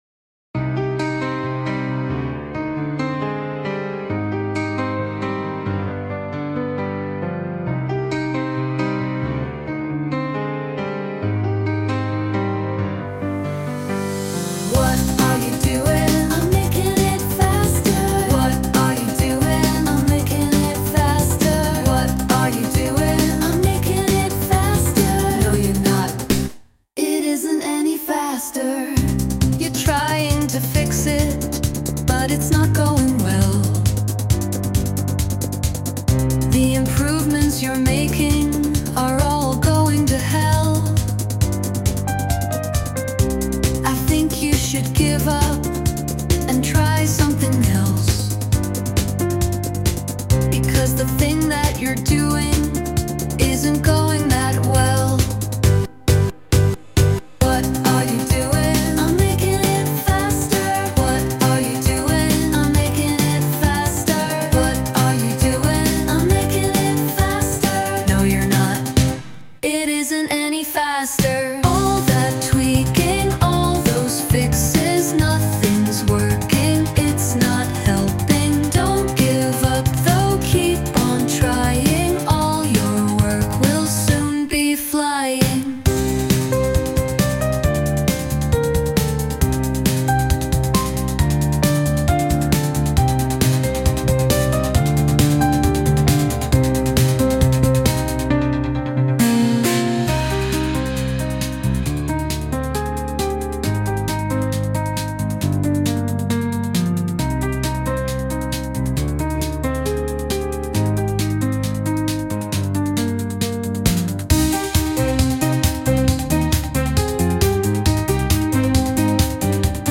Sung by Suno
Turbulent_Ogre_(inst)_(Cover)_mp3.mp3